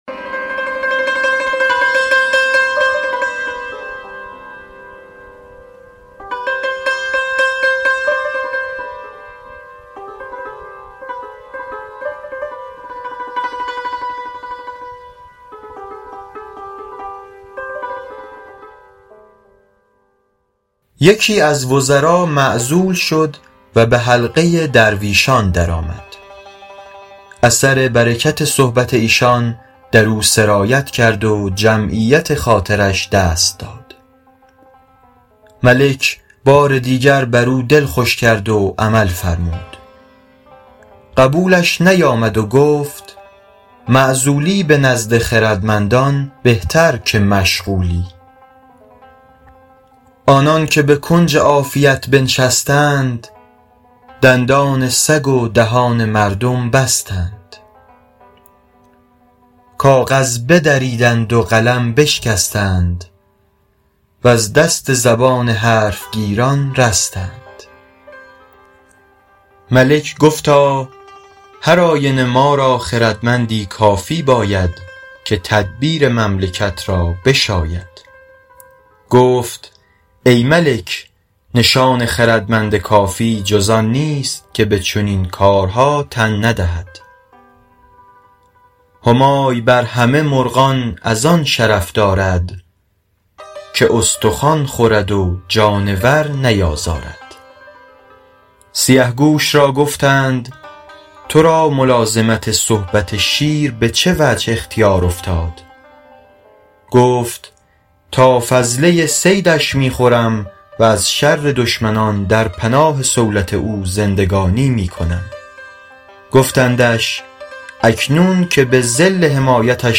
سعدی » گلستان » باب اول در سیرت پادشاهان » حکایت شمارهٔ ۱۵ با خوانش